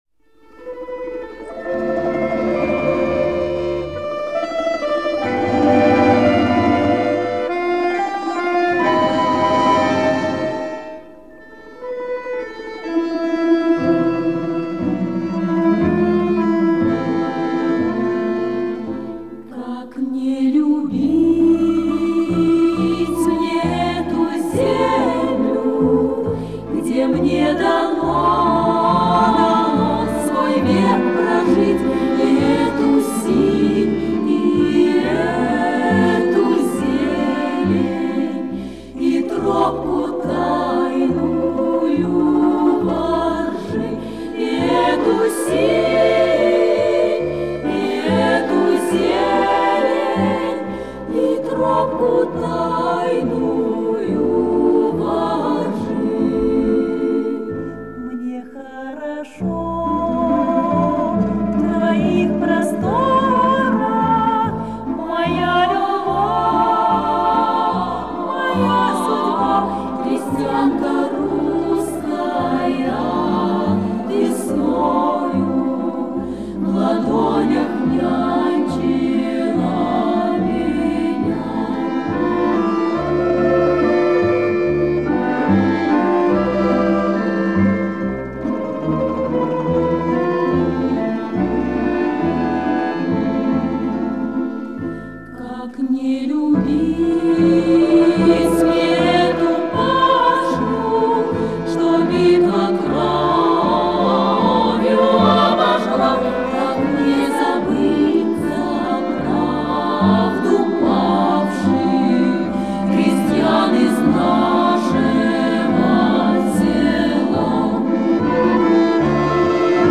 Песня о красоте любимой Родины